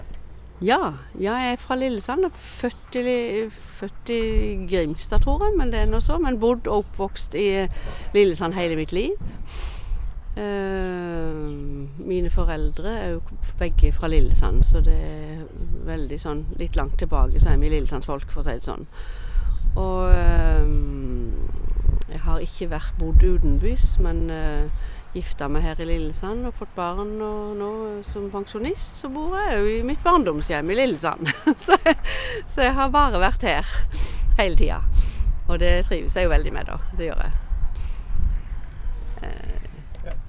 Dialekten i Lillesand